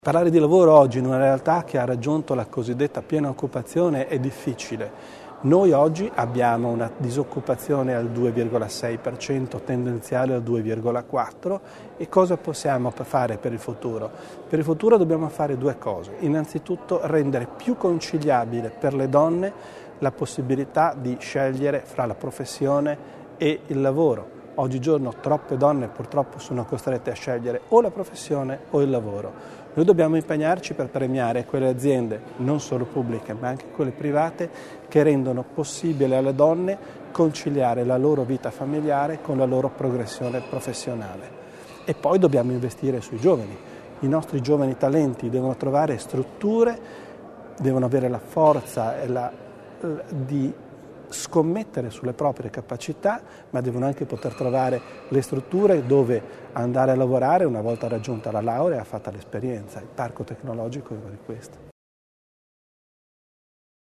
Disoccupazione, dimensione e demografia: sono le "3 d" sui cui l'Alto Adige deve intervenire nel settore del mercato del lavoro, secondo la definizione dell'assessore provinciale Roberto Bizzo. Nel Colloquio di metà legislatura con i giornalisti oggi (28 luglio) a Bolzano Bizzo ha fatto il punto delle misure anticrisi varate dalla Provincia e delineato le prossime azioni per rafforzare l'occupazione locale, a cominciare dal lavoro per gli over 50.